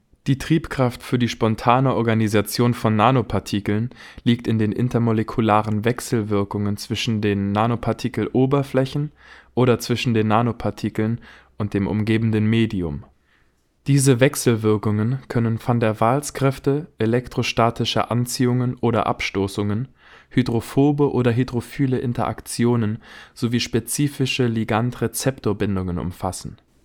Deutscher Sprecher, Helle Stimme, Junge Stimme, Mittel alte Stimme, Schauspieler, Sänger, Werbesprecher, Off-Sprecher, Dokumentation, Geschichte, Buch
Sprechprobe: Industrie (Muttersprache):